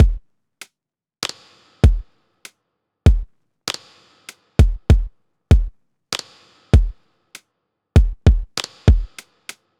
Index of /99Sounds Music Loops/Drum Loops/Hip-Hop